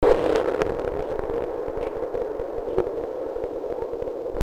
The full recording, and the whistle sounds can be heard at
The conditions were pretty calm and unchanged over that time of recording.